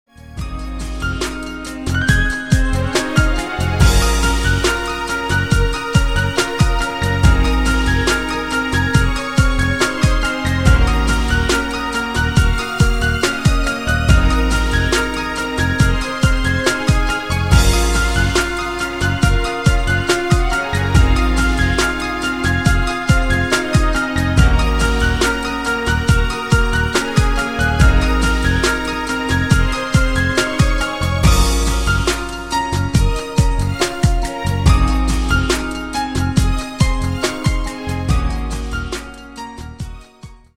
• Качество: 192, Stereo
грустные
спокойные
инструментальные
пианино